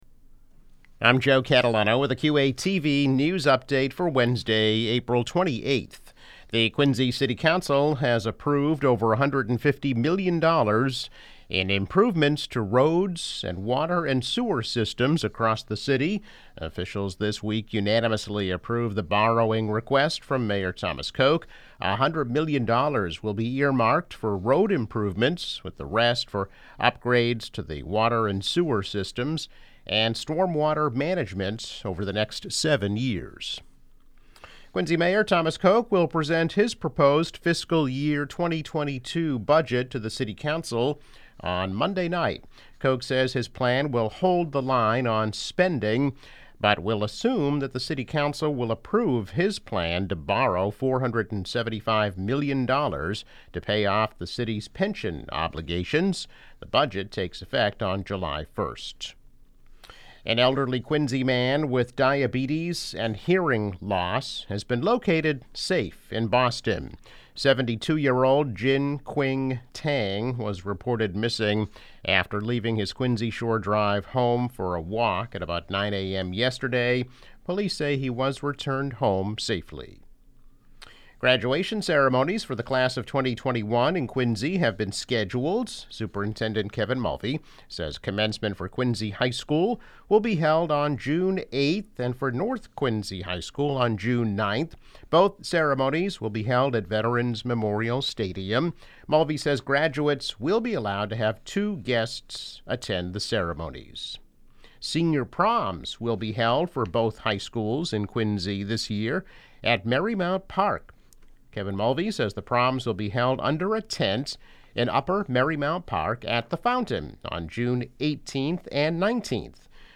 News Update - April 28, 2021